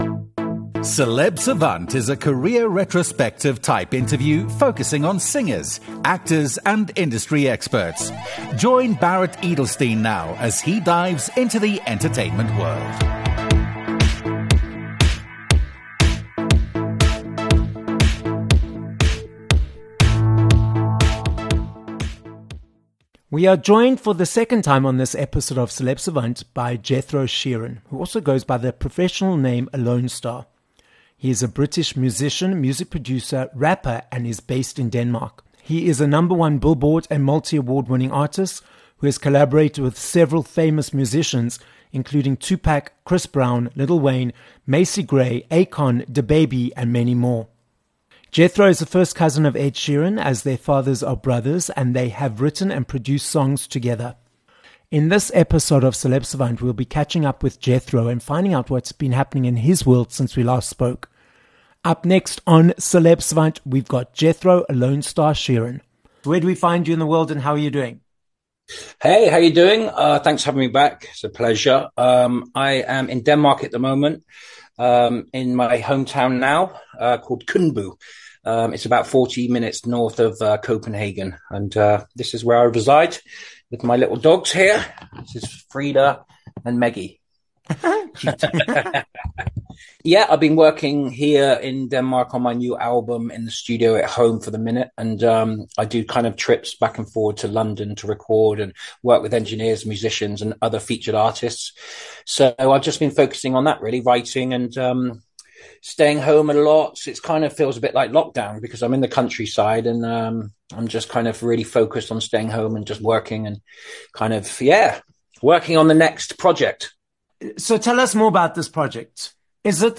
9 Apr Interview